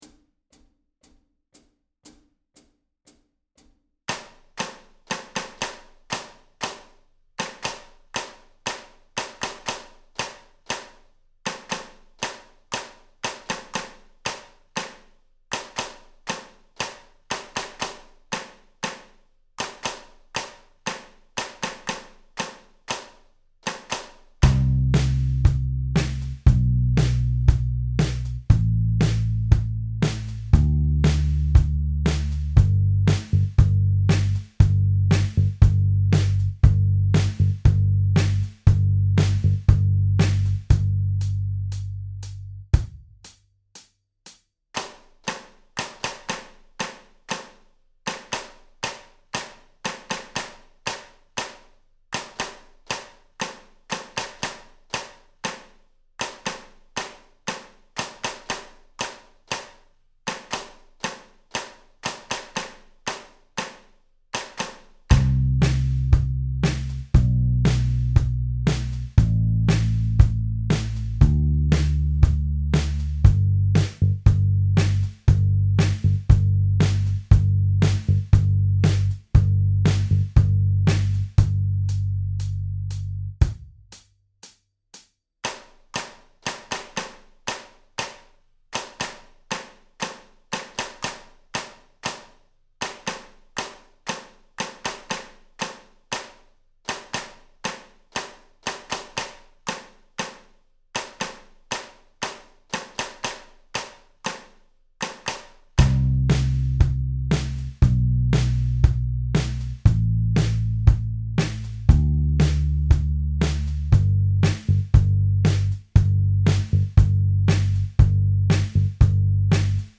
Audio Practice Tracks
Each practice track has a 2 measure count-in, and then the clapping percussion begins.
Moderate-Slow Tempo (118bpm) - download, or press the play button below to stream: